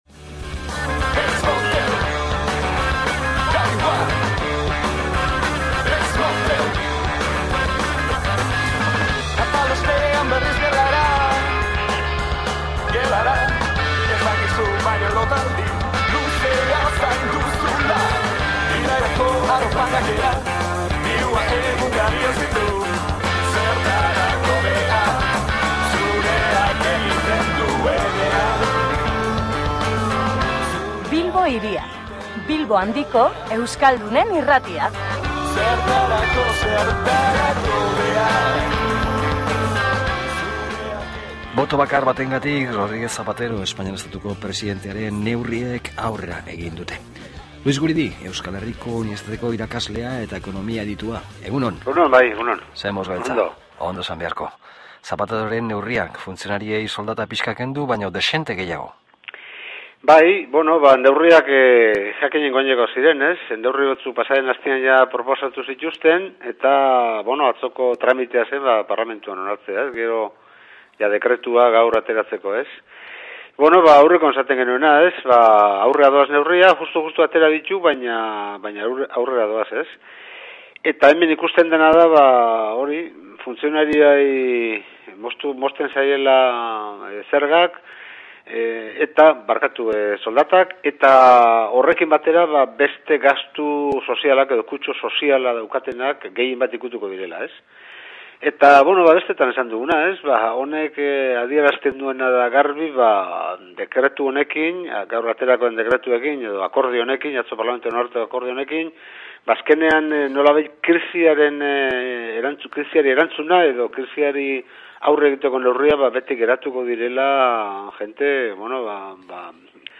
solas